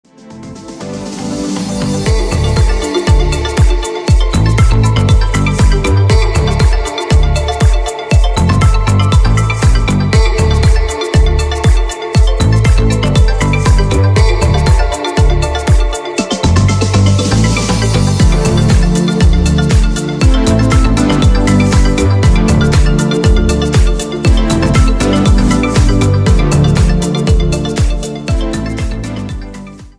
• Качество: 160, Stereo
ритмичные
deep house
атмосферные
Electronic
без слов
красивая мелодия